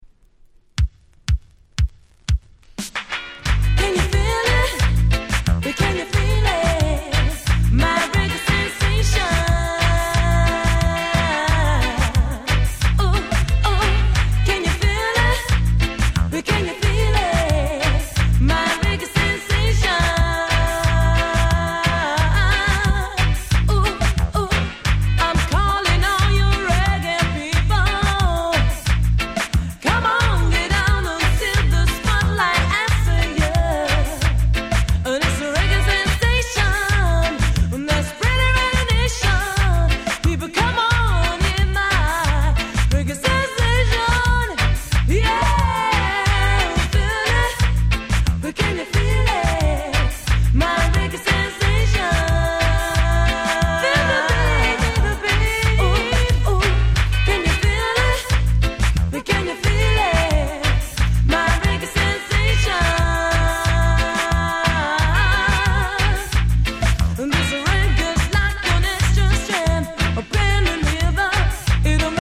Very Nice Cover Reggae !!